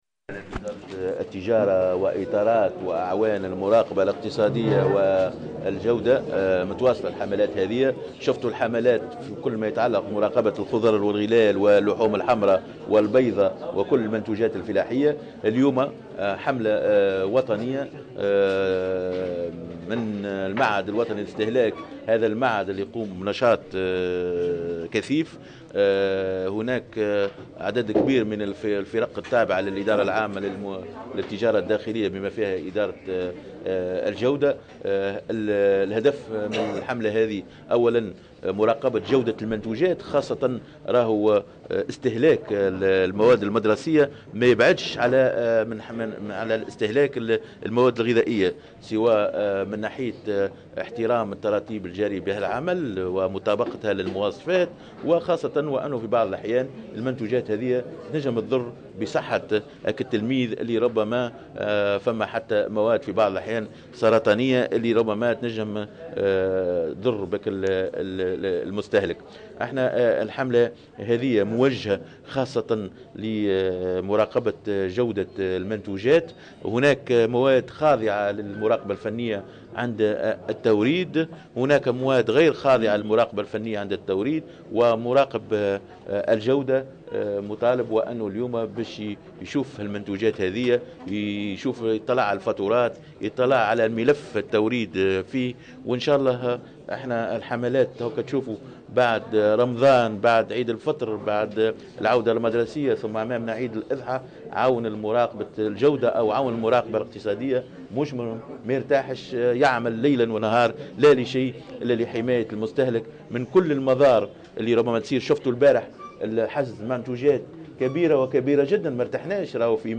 وقال وزير التجارة رضا الأحول إن الهدف من هذه الحملة هو مراقبة جودة المنتوجات و التصدي للمواد الاستهلاكية الغير مطابقة للمواصفات حفاظا على صحة المستهلك.